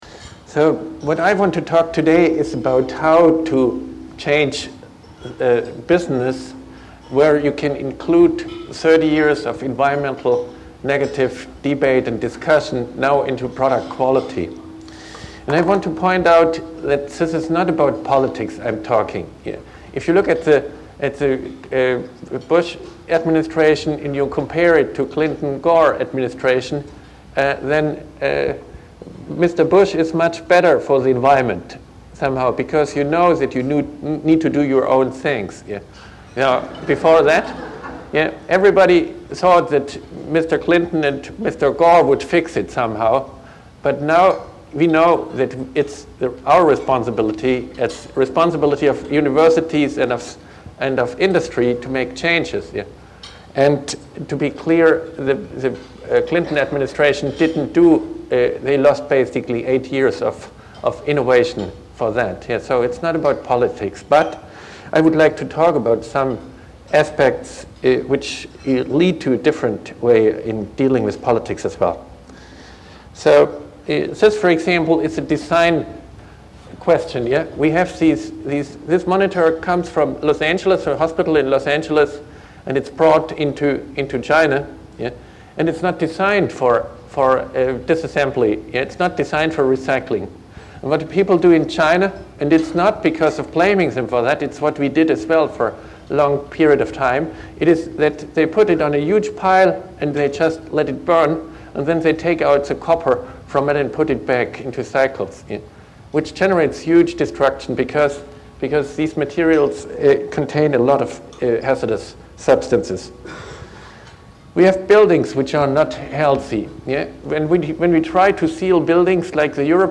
Recorded Wednesday February 16, 2005 Braun Auditorium - Stanford University